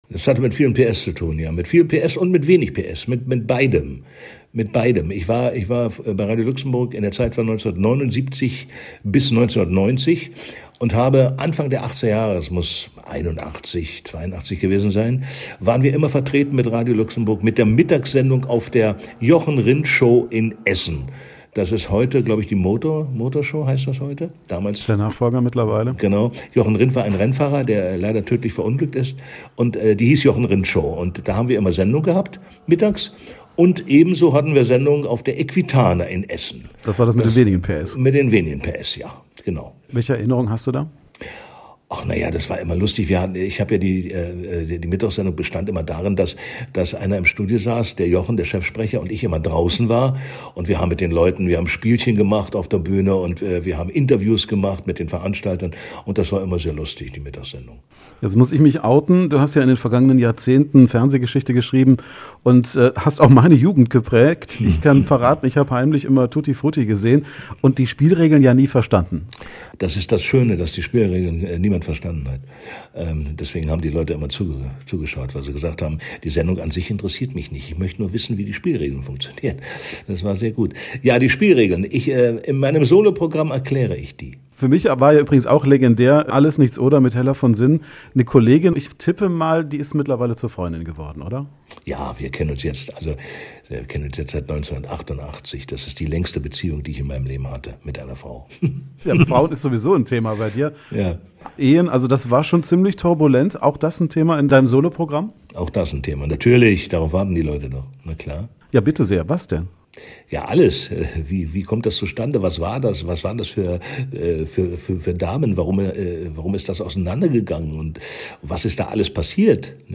Im Radio Essen-Interview gibt es einen Blick hinter die Kulissen